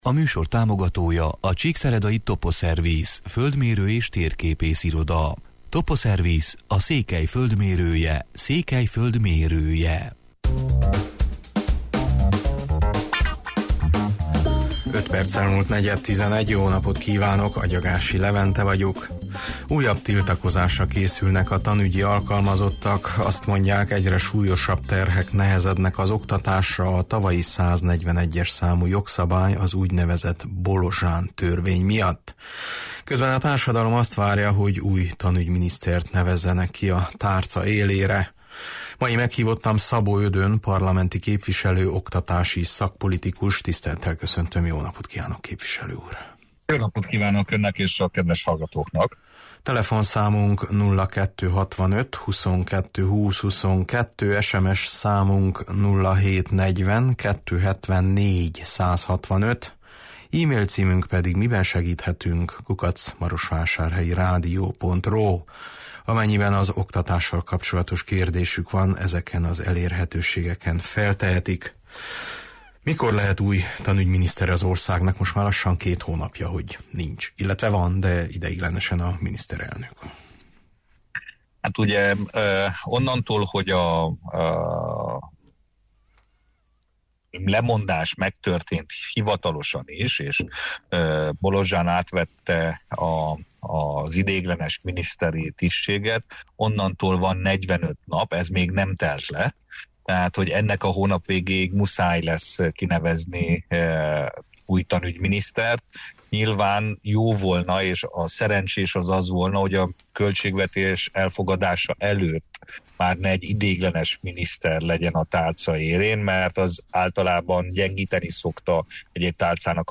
Mai meghívottam Szabó Ödön parlamenti képviselő, oktatási szakpolitikus: